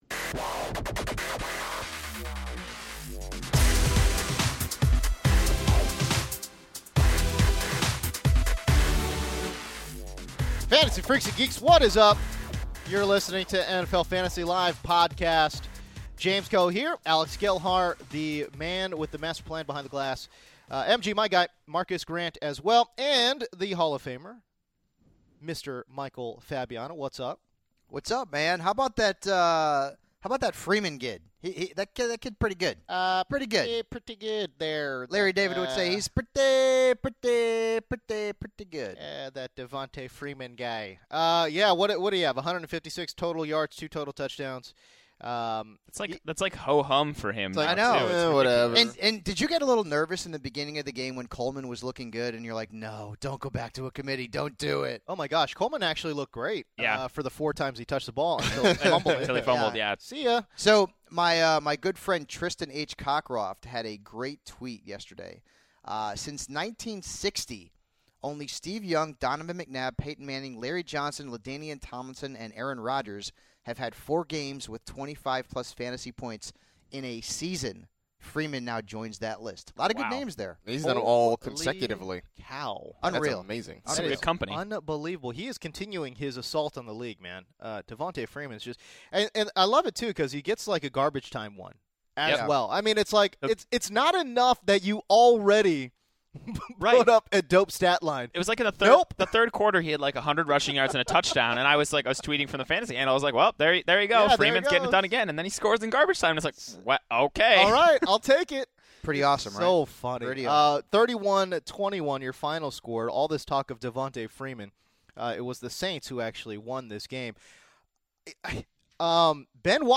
The NFL Fantasy LIVE crew has assembled to recap the Thursday Night Football game between the Atlanta Falcons and New Orleans Saints. After that, the guys react to the top headlines and injury news of the week before highlighting some of the matchups that excite and scare them for Week 6. They welcome two guests into the podcast as well, but you’ll have to listen to find out who.